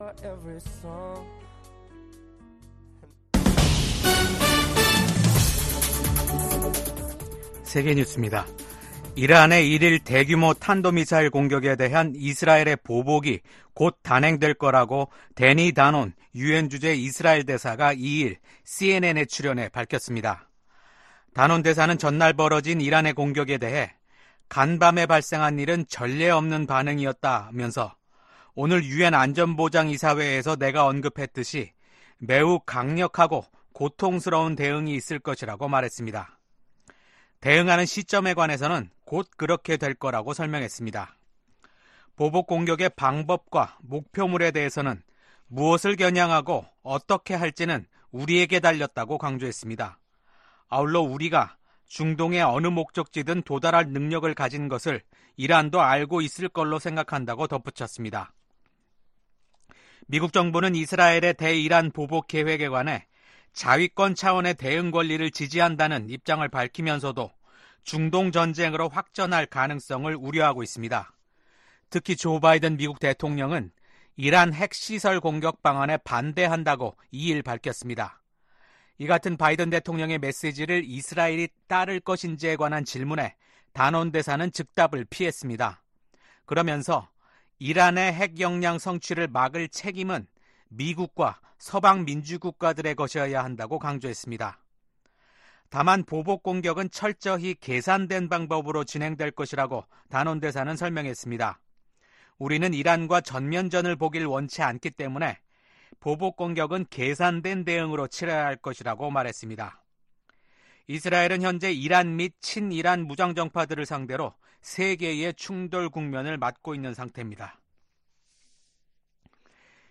VOA 한국어 아침 뉴스 프로그램 '워싱턴 뉴스 광장' 2024년 10월 4일 방송입니다. 미국 국무부의 커트 캠벨 부장관은 러시아 군대의 빠른 재건이 중국, 북한, 이란의 지원 덕분이라고 지적했습니다. 조 바이든 미국 대통령이 새로 취임한 이시바 시게루 일본 총리와 처음으로 통화하고 미한일 협력을 강화와 북한 문제를 포함한 국제 정세에 대응하기 위한 공조를 재확인했습니다.